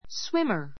swimmer swímə r ス ウィ マ 名詞 泳ぐ人, 泳ぎ手 a good [poor] swimmer a good [poor] swimmer 泳ぎの上手な[下手な]人 Bob is a very good swimmer.